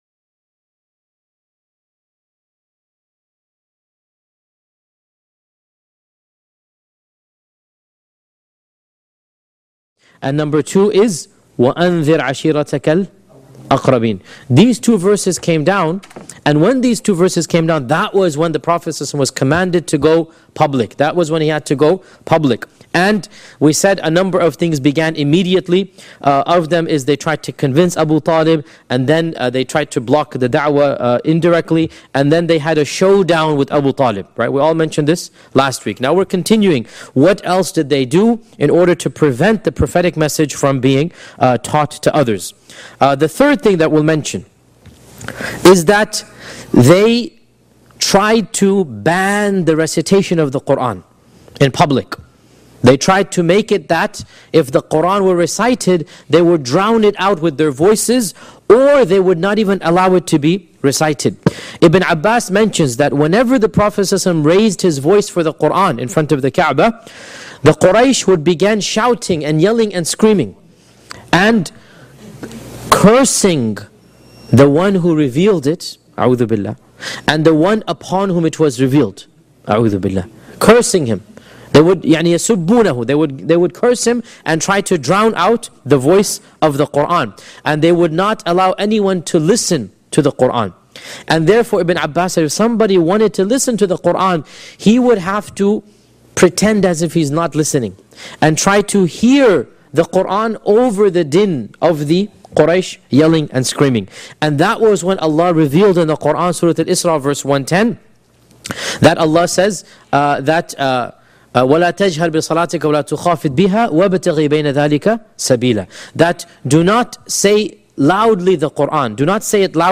This Seerah lecture covers the dramatic transition from private to public da’wah and the Quraysh’s escalating efforts to silence the message of Islam.